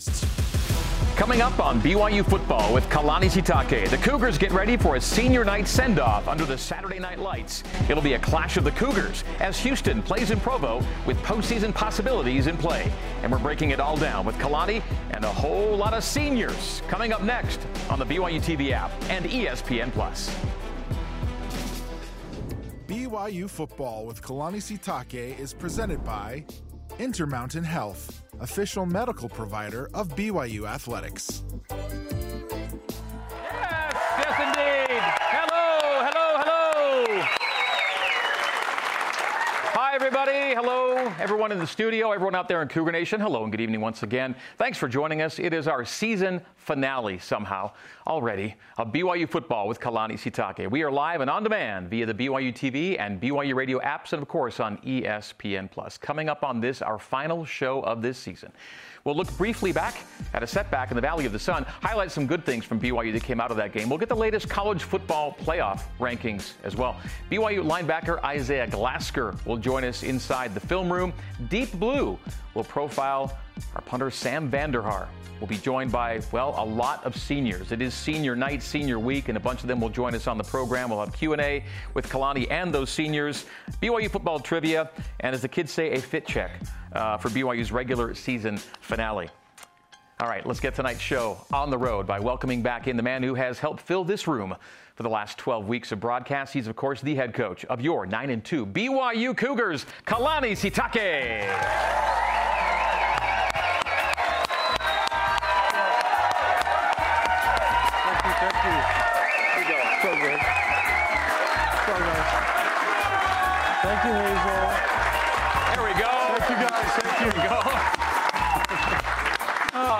a live audience in Studio C.